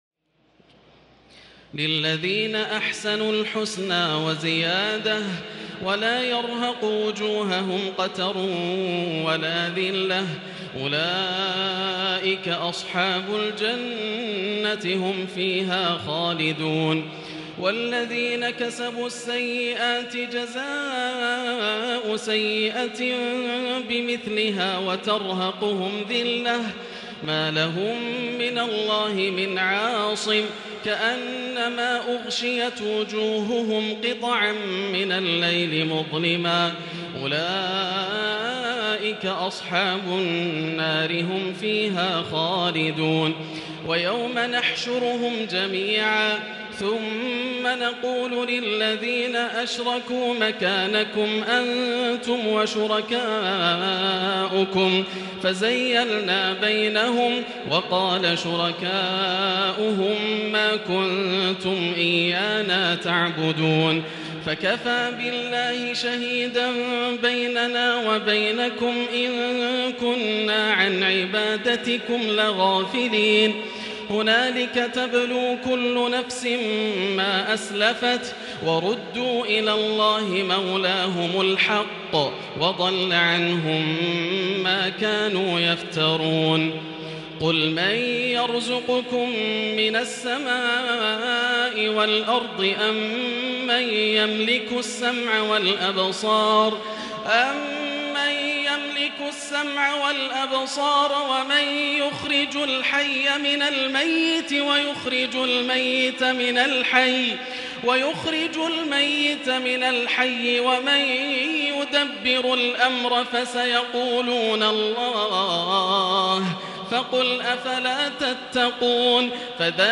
تراويح الليلة العاشرة رمضان 1440هـ من سورة يونس (26-109) Taraweeh 10 st night Ramadan 1440H from Surah Yunus > تراويح الحرم المكي عام 1440 🕋 > التراويح - تلاوات الحرمين